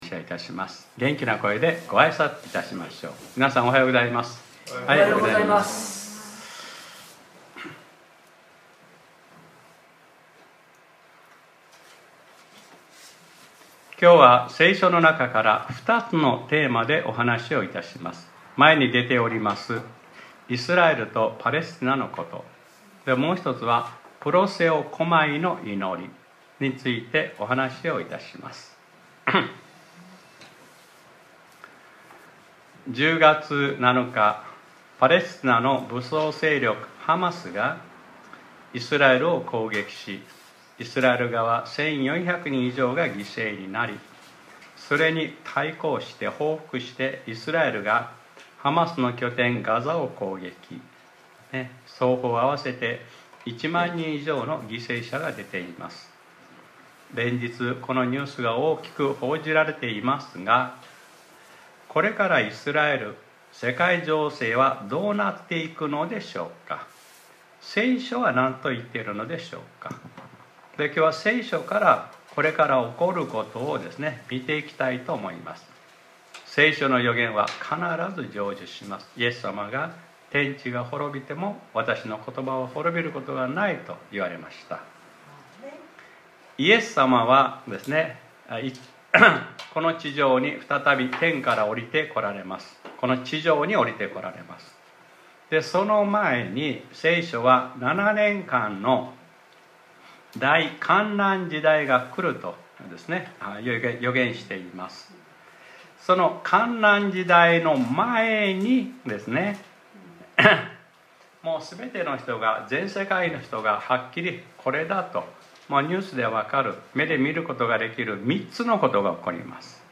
2023年11月05日（日）礼拝説教『 プロセオコマイの祈り 』